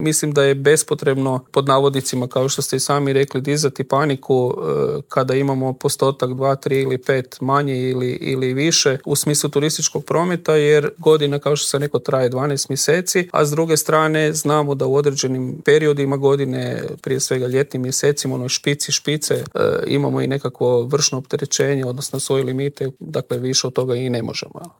Brojke za prvih pola godine su izrazito dobre, a u Intervjuu tjedna Media servisa prokomentirao ih je direktor Hrvatske turističke zajednice Kristjan Staničić: